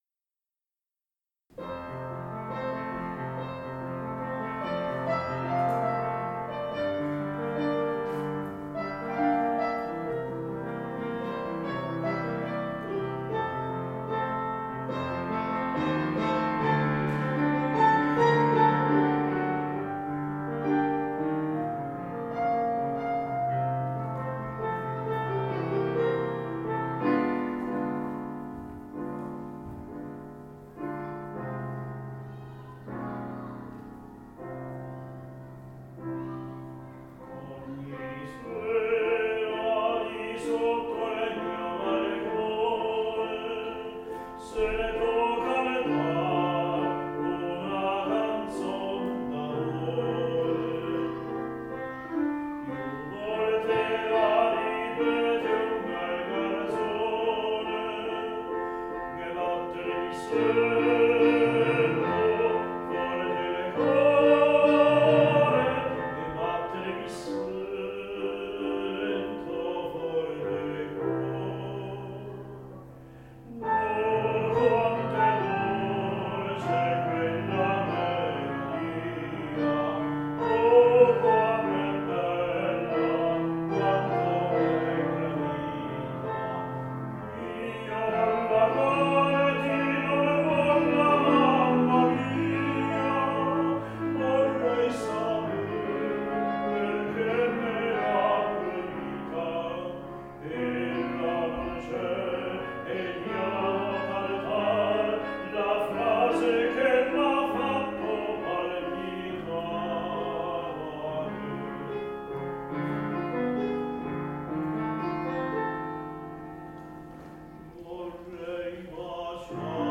Romanza "Musica Proibita" di Stanislao Gastaldon
tenore
Dal Concerto del 16 ottobre 2016